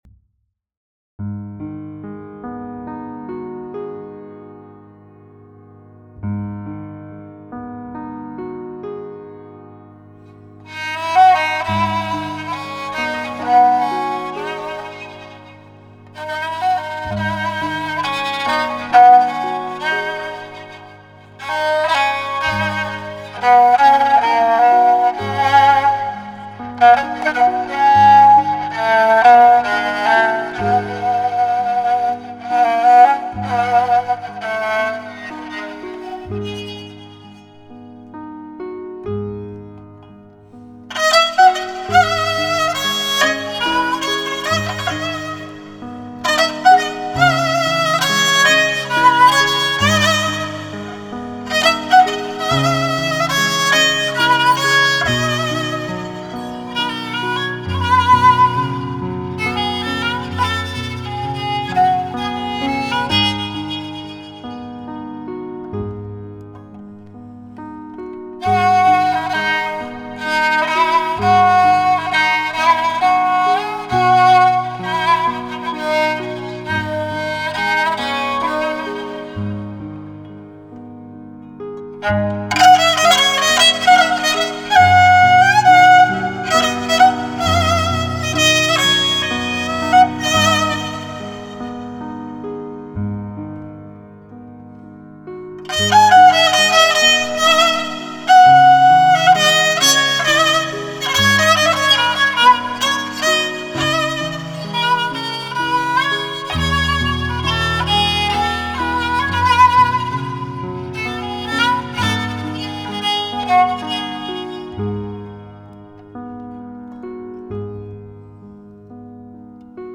قطعه موسیقی بی‌کلام